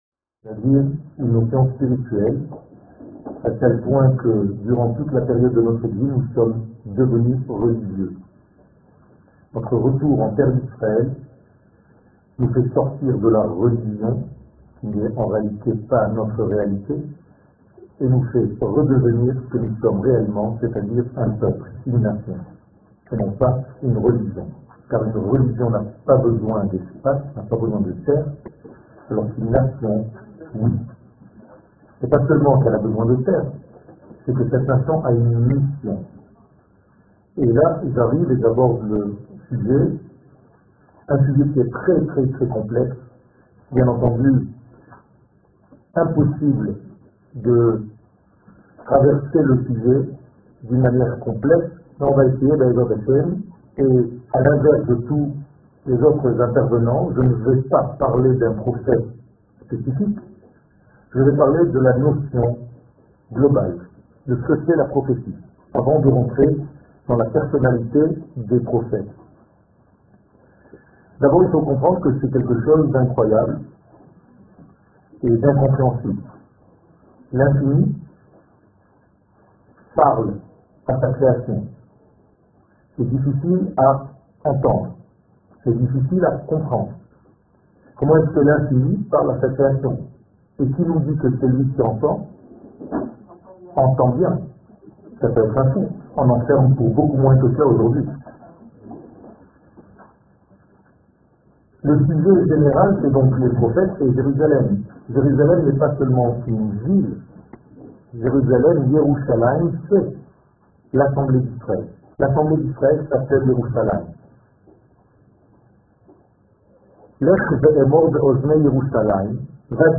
Conférence sur la prophétie
Conference-sur-la-prophetie.m4a